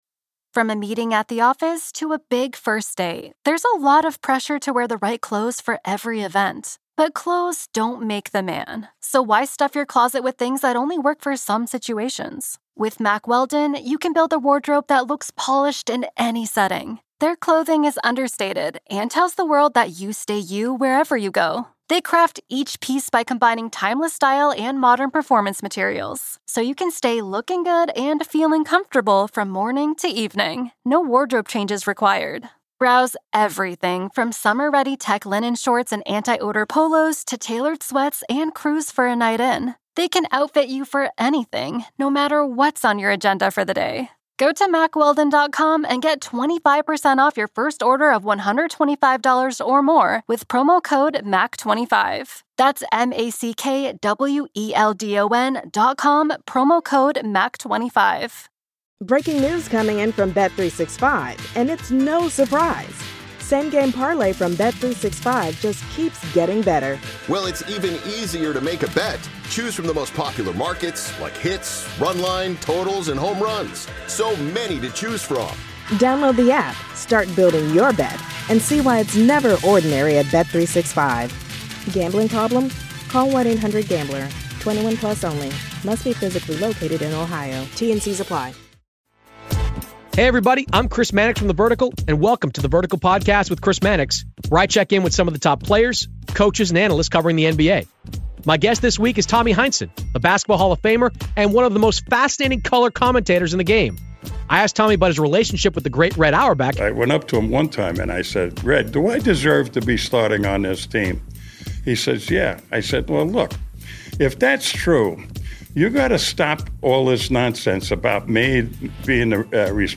Former Boston Celtic Tommy Heinsohn The Crossover NBA Show SI NBA Basketball, Sports 4.6 • 641 Ratings 🗓 13 December 2016 ⏱ 48 minutes 🔗 Recording | iTunes | RSS 🧾 Download transcript Summary Joining Chris Mannix on The Vertical this week is the former Boston Celtics player and coach. Chris talks with Tommy about Bill Russell, Red Auerbach and 60 years with the Celtics.